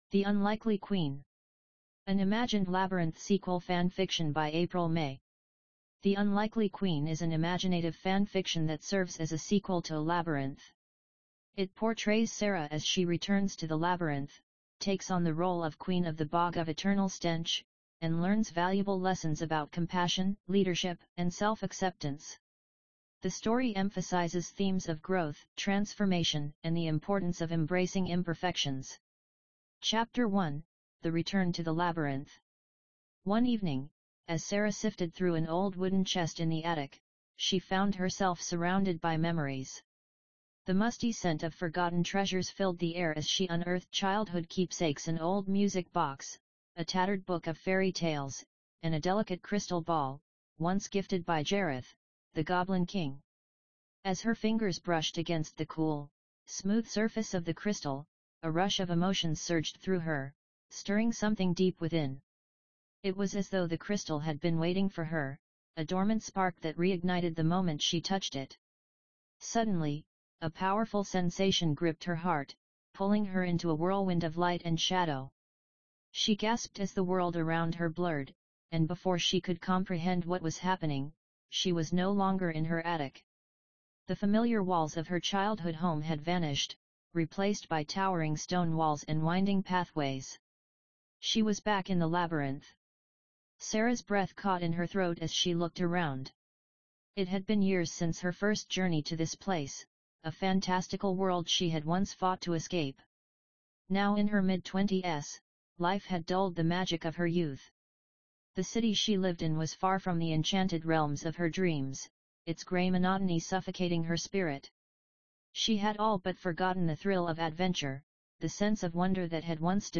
Listen to this audio book Download PDF or Print this page ⏬Download MP3 ❦ Chapter 1: The Return to the Labyrinth One evening, as Sarah sifted through an old wooden chest in the attic, she found herself surrounded by memories.